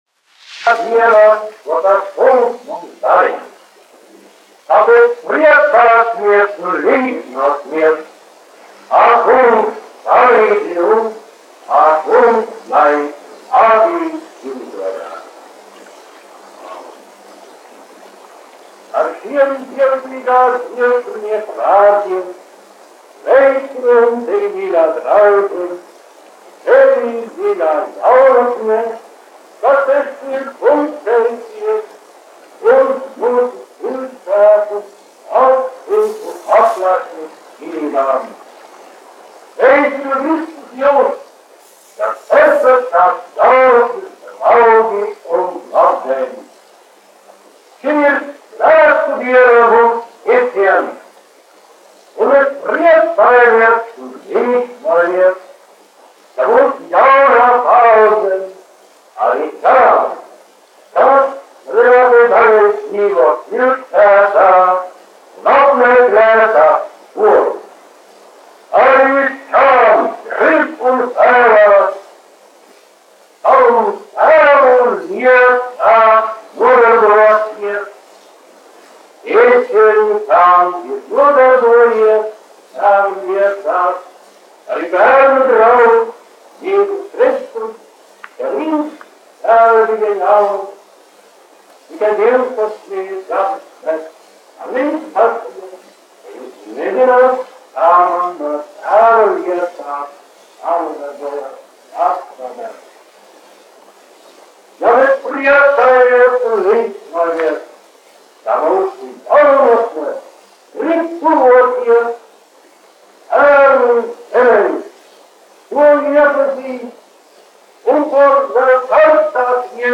Pieslēgums dievkalpojumam Bauskas baznīcā sakarā ar Bauskas ģimnāzijas un pamatskolas mazpulku atklāšanas svinībām.
• Lutheran Church -- Sermons
• audio disc